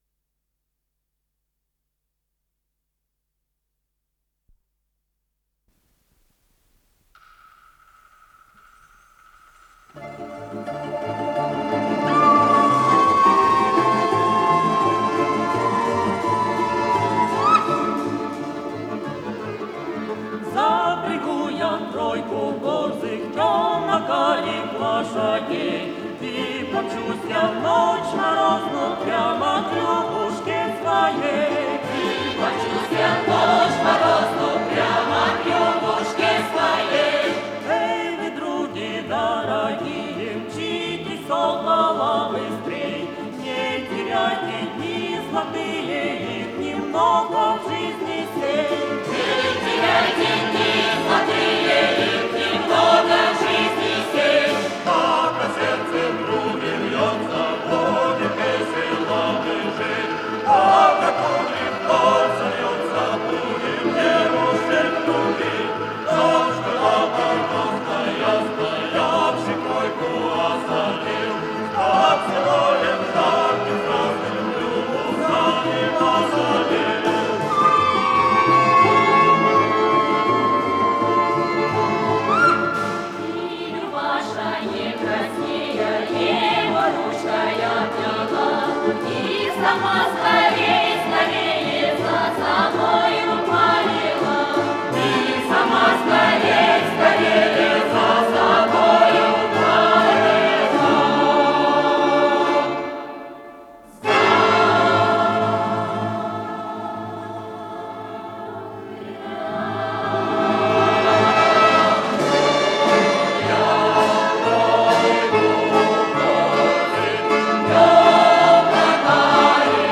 ИсполнителиНародный песенно-инструментальный ансамбль "Сибирь"
Скорость ленты38 см/с